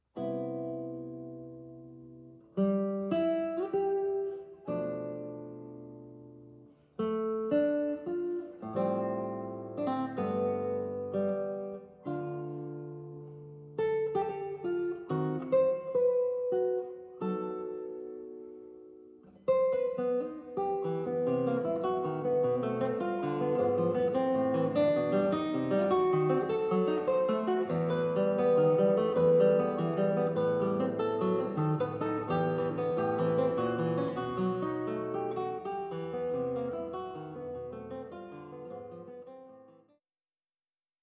für Gitarre
guitar